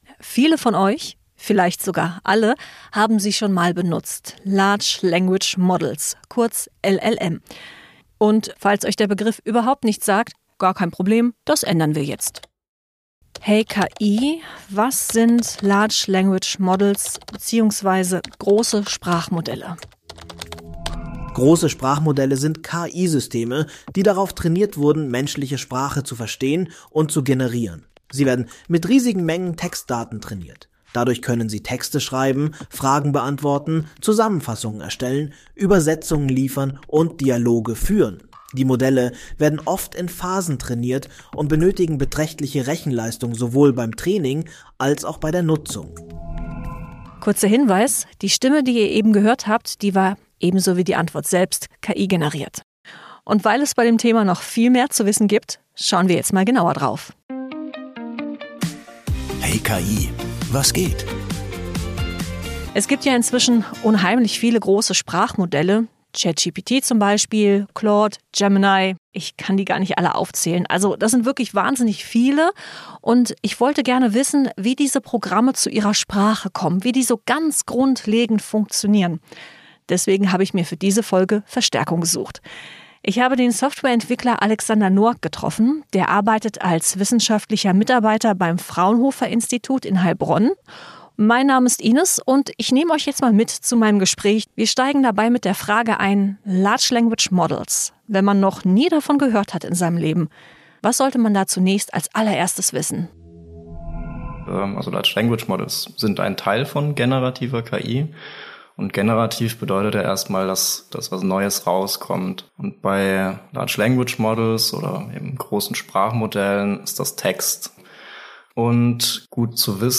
Er spricht im Interview darüber, wie Sprachmodelle zu ihrer Sprache kommen. Außerdem erklärt er, was das mit Statistik zu tun hat und ob man sich immer auf KI verlassen kann.